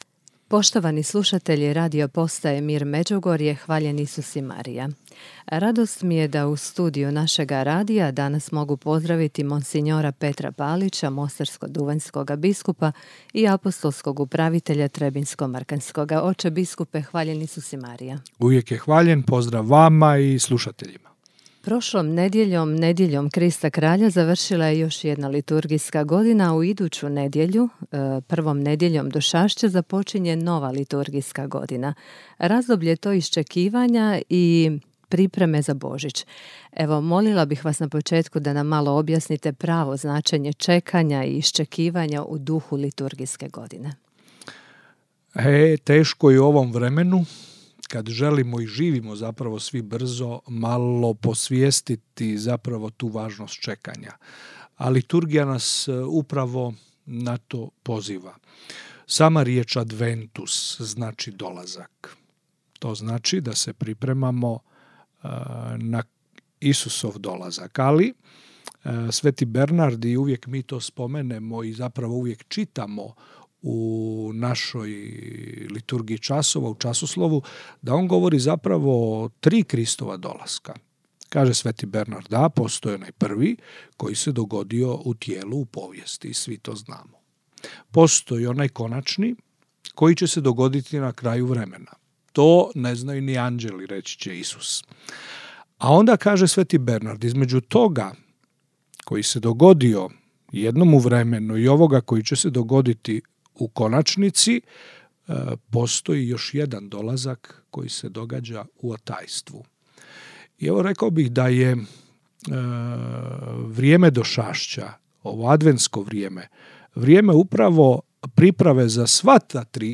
Upravo smo o temi došašća, događajima koji su obilježili jubilarnu godinu, ali i o nekim drugim aktualnim temama u našem programu razgovarali s mons. Petrom Palićem, mostarsko-duvanjskim biskupom i apostolskim upraviteljem trebinjsko-mrkanskim.